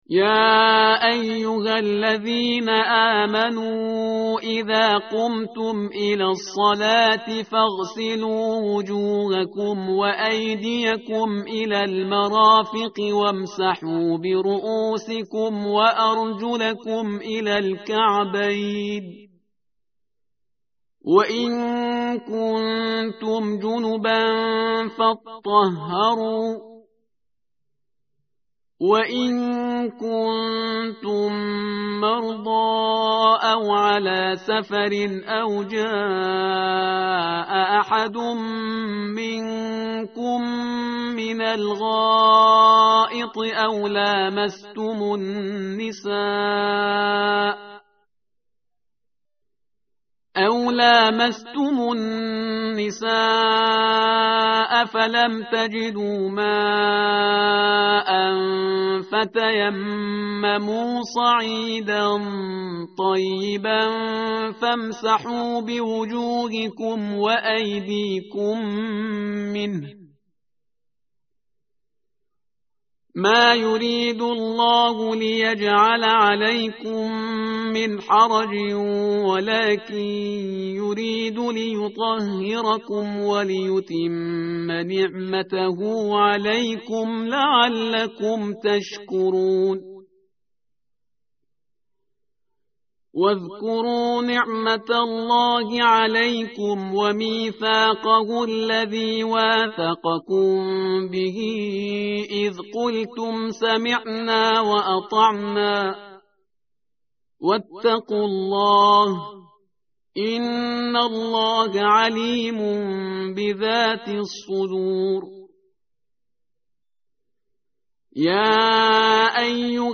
متن قرآن همراه باتلاوت قرآن و ترجمه
tartil_parhizgar_page_108.mp3